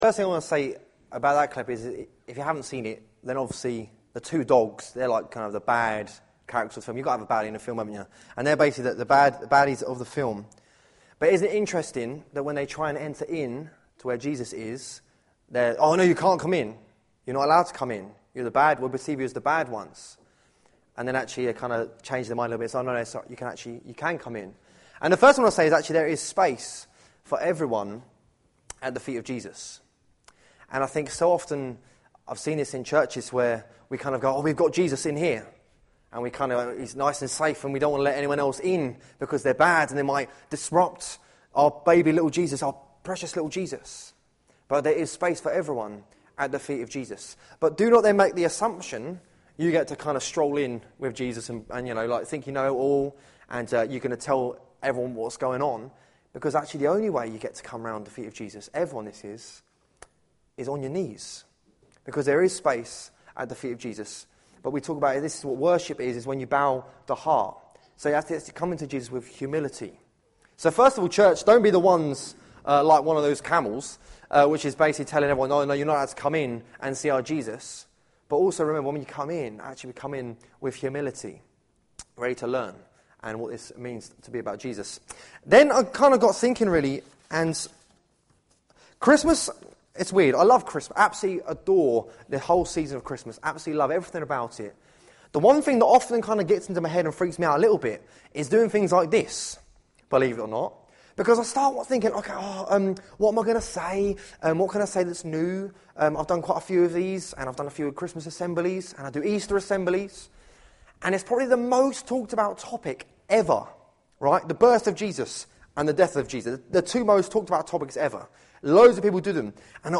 Carol Service